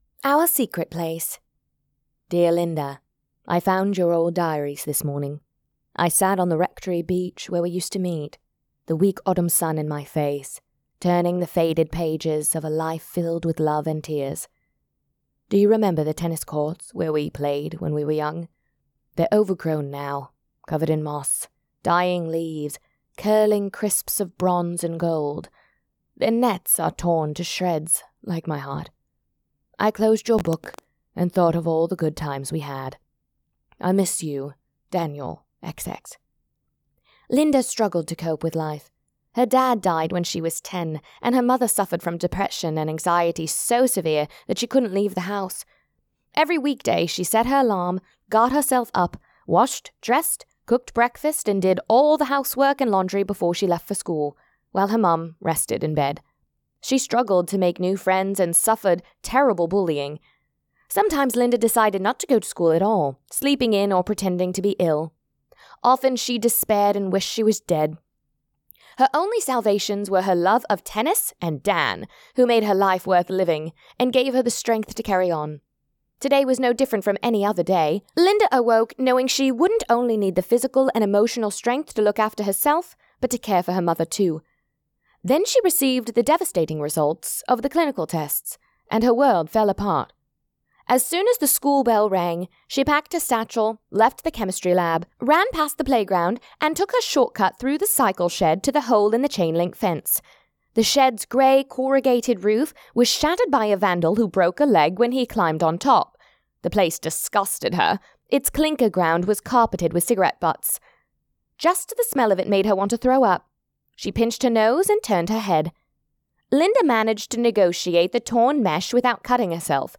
Our Secret Place LIVE